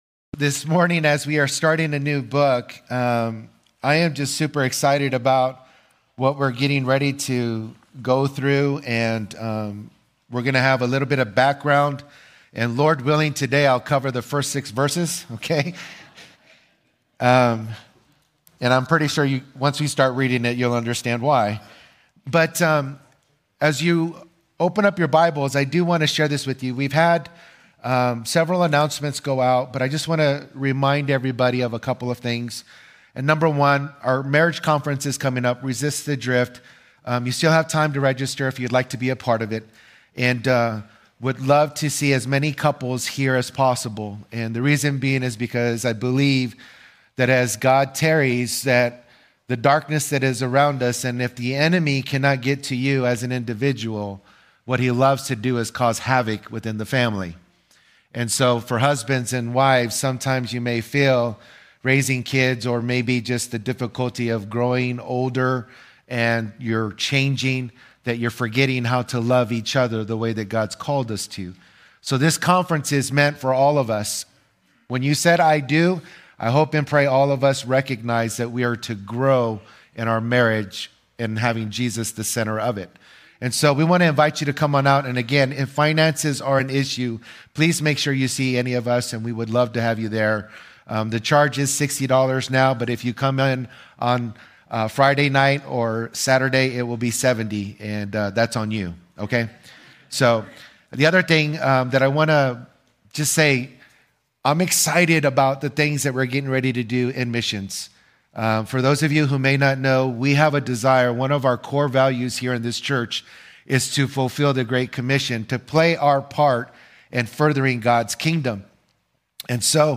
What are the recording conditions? Related Services: Sunday Mornings